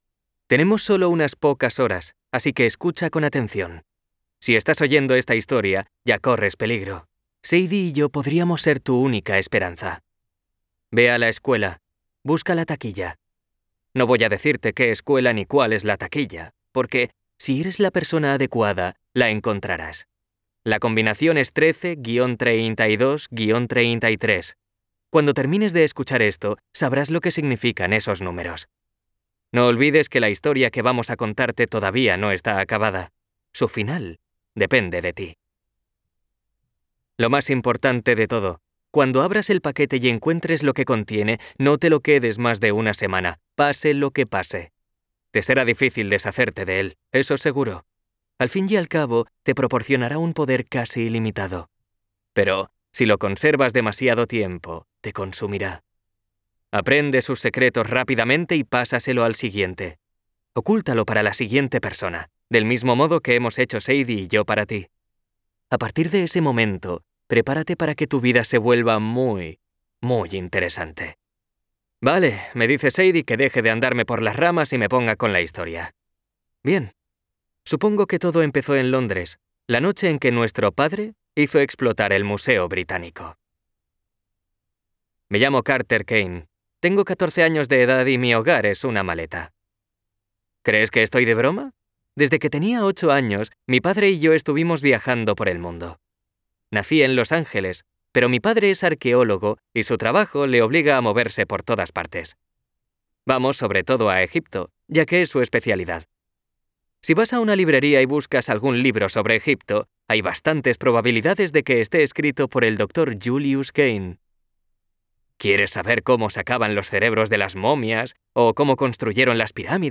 TIPO: Audiolibro CLIENTE: Penguin Random House Grupo Editorial Audio ESTUDIO: Booka Audiobooks